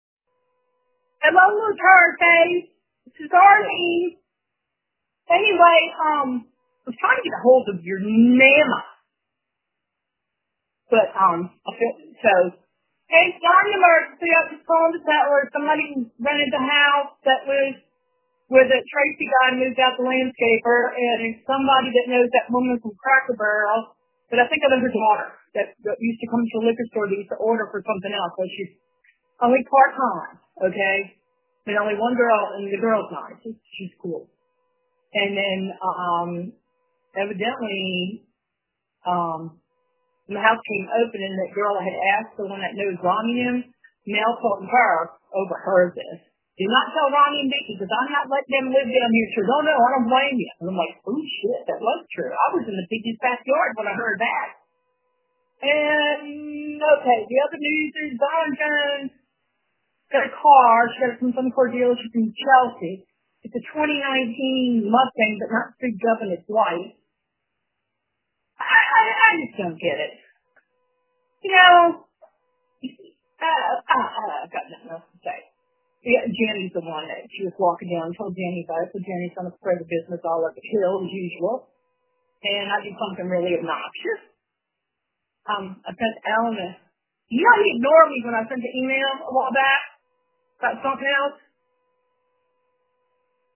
Emergency.m4a
Emergency Alert